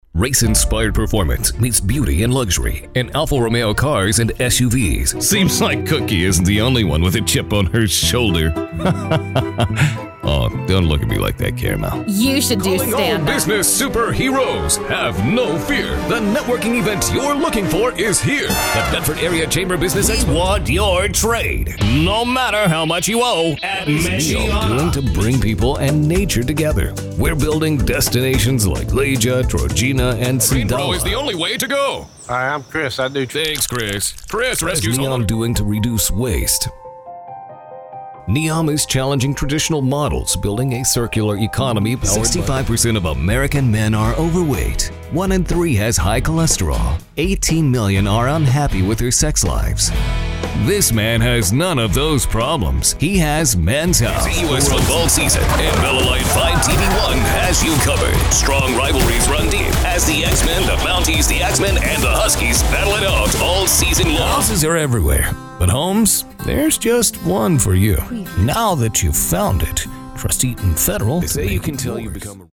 Since 2001, I’ve worked nationally and internationally as a voice over talent, delivering broadcast-ready reads for TV, radio, automotive, and documentary projects from a professional studio.
Commercial
Middle Aged